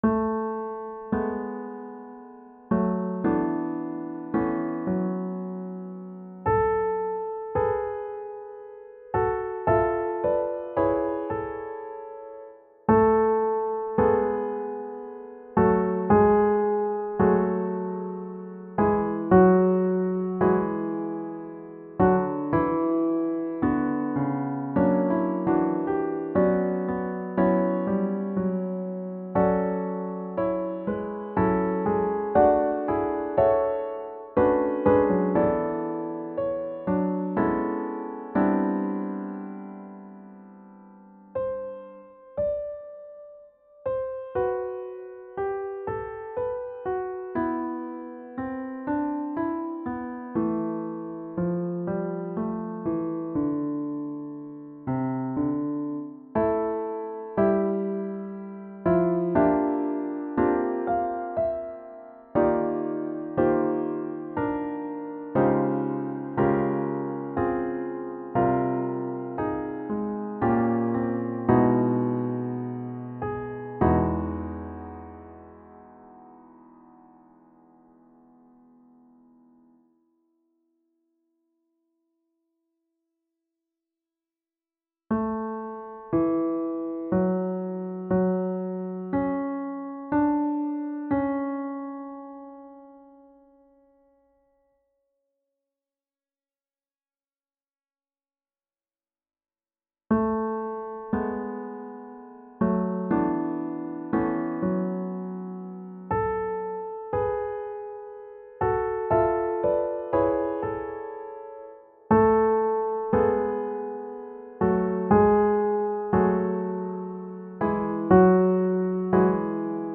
• Music Type: Choral
• Voicing: SATB with divisi
• Accompaniment: a cappella
Written for eight voices